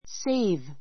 séiv セ イ ヴ